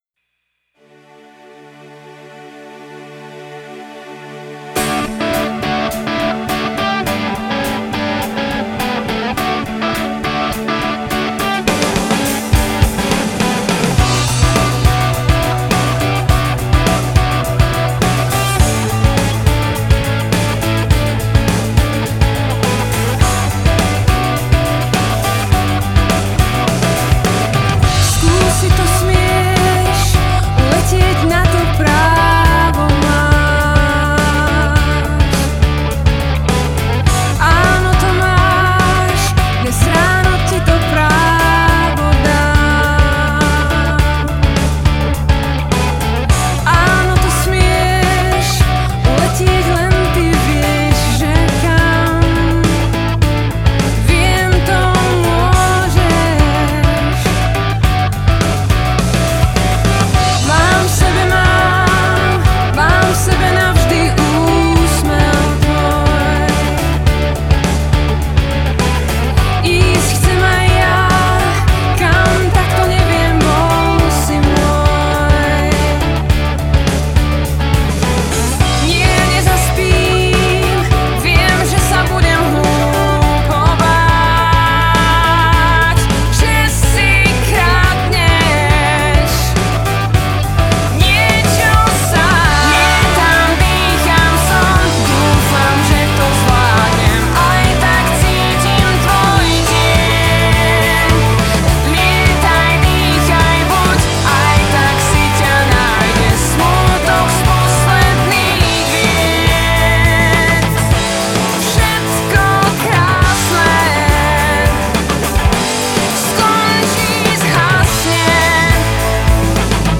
gitary
basgitary
klávesy
bicie